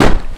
pistol_shoot.wav